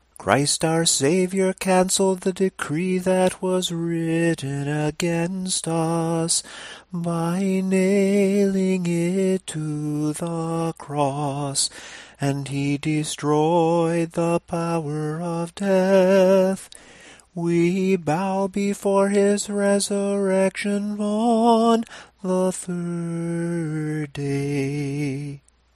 This is the common melody for singing stichera in tone 2.
Here is the second sticheron in Tone 2:
Tone_2_samohlasen_Sunday_sticheron_9.mp3